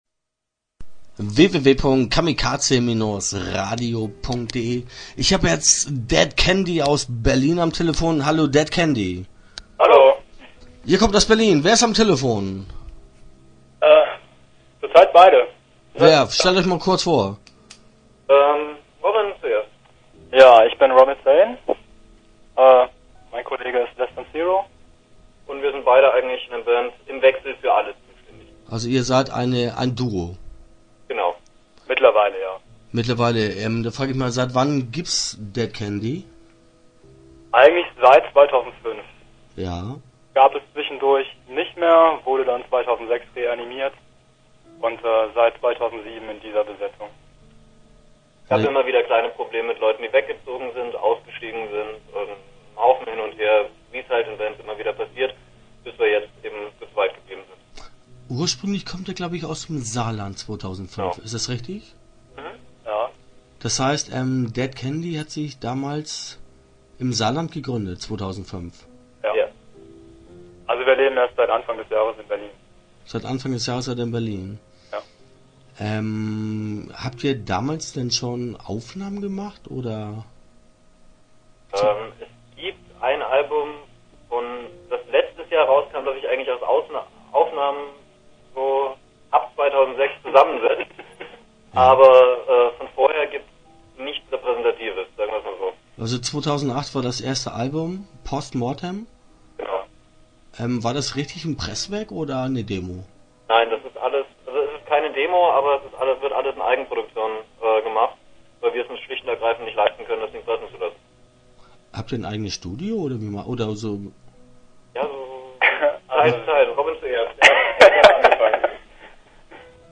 Interview Teil 1 (10:42)